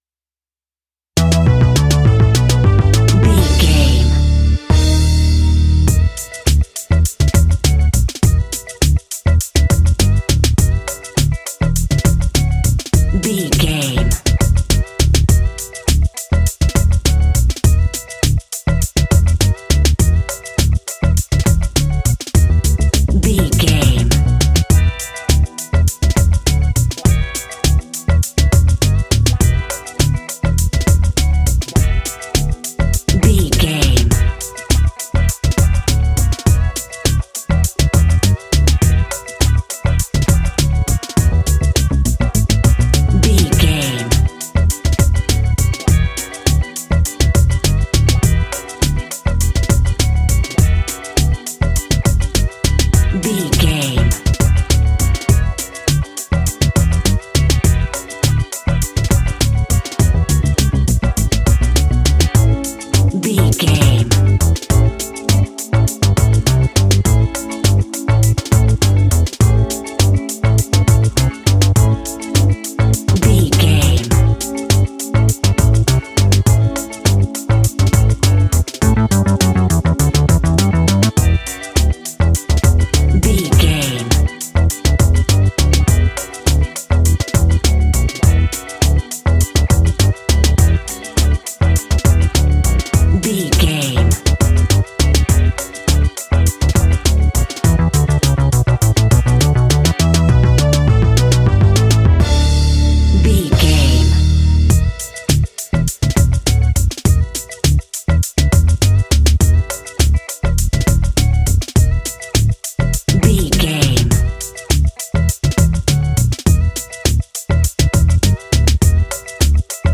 Aeolian/Minor
relaxed
smooth
synthesiser
drums